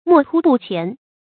墨突不黔 注音： ㄇㄛˋ ㄊㄨ ㄅㄨˋ ㄑㄧㄢˊ 讀音讀法： 意思解釋： 見「墨突」。